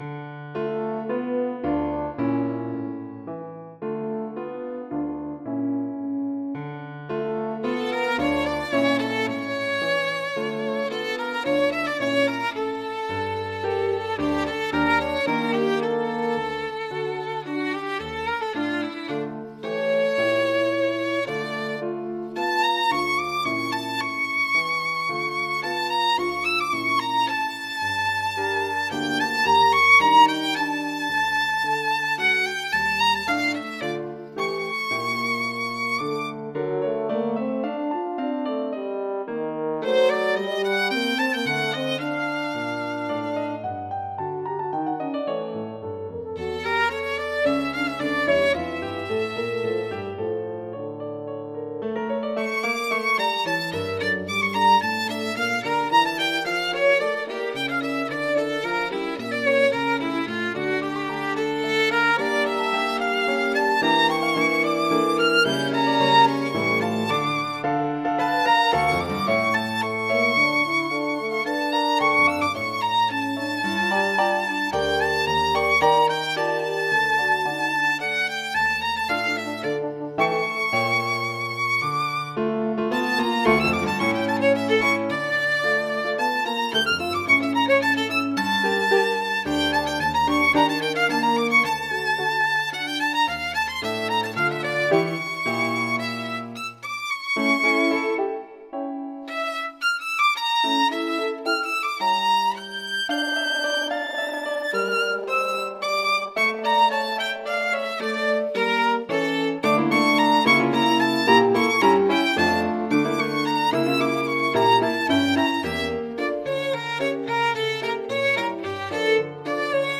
Trio for Violin, F Horn and piano – 3:40 minutes